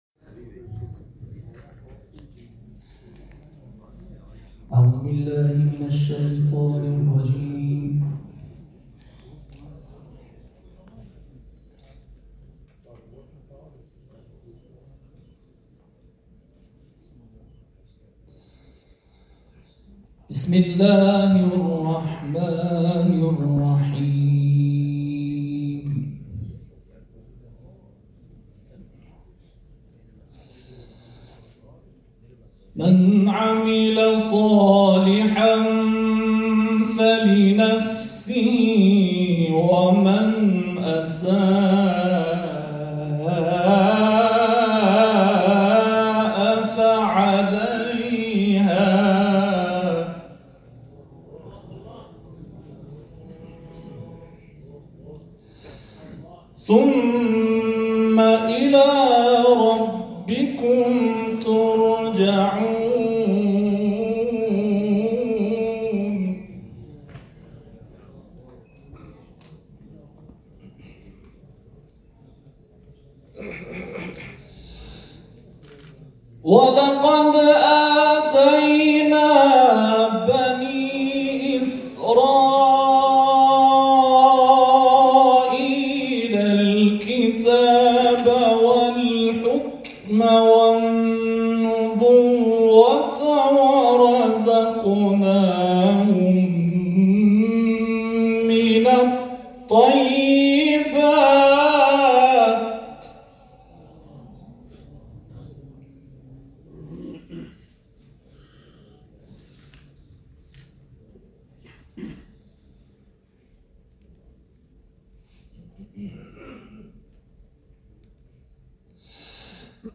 چهل و پنجمین دوره مسابقات سراسری قرآن